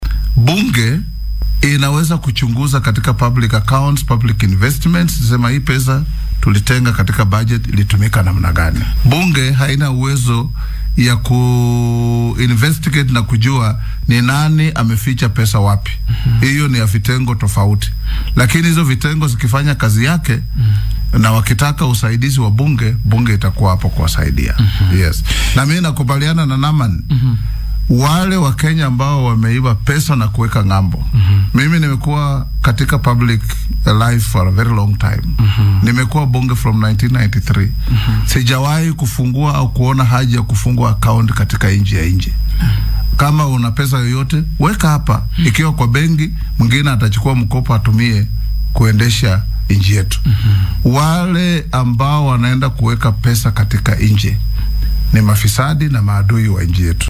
Guddoomiyaha baarlamaanka dalka Moses Wetang’ula oo wareysi gaar ah siiyay warbaahinta dowladda ee KBC ayaa wacad ku maray inuu ilaalin doono madax bannaanida baarlamaanka.